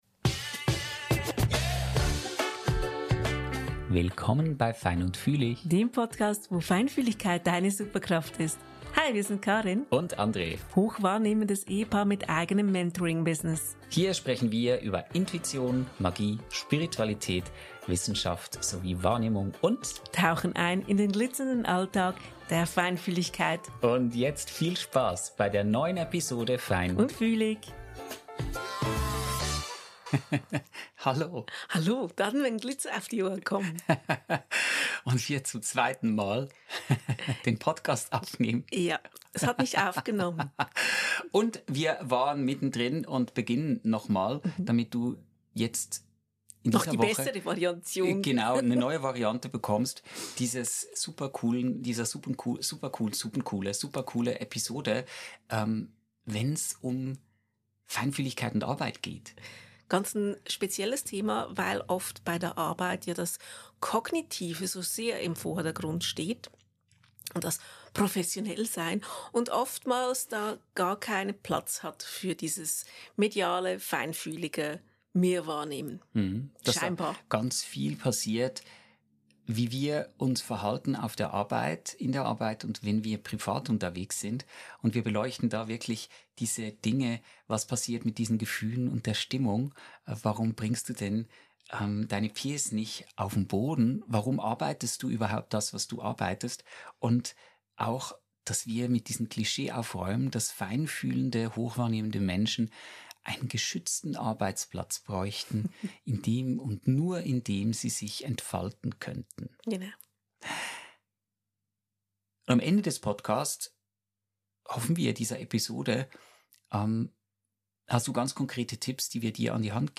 Neu gibts die Durchsage zum Herzen mit Harfenklängen.